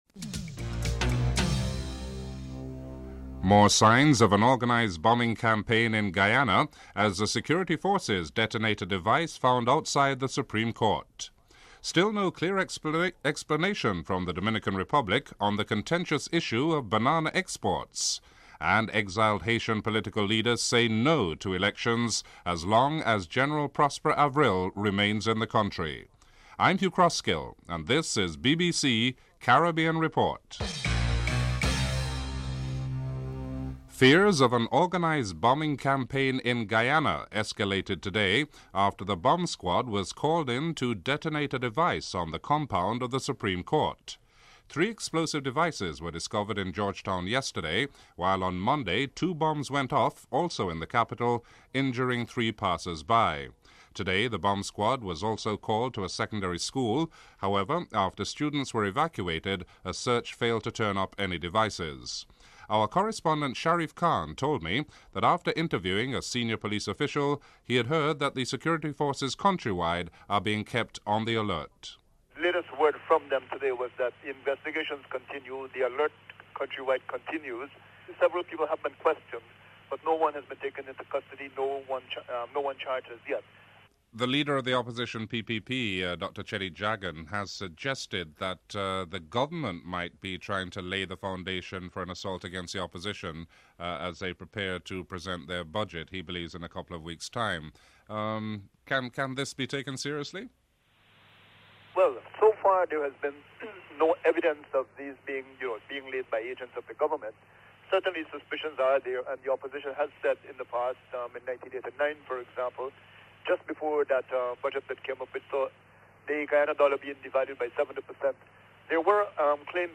1. Headlines (00:00 - 00:33)